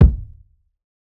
Sold Kick.wav